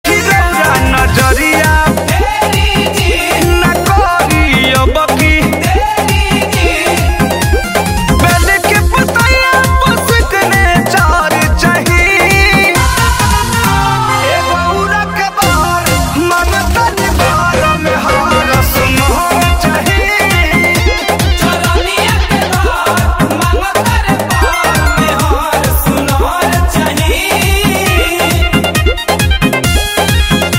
Bhojpuri Bolbum Ringtones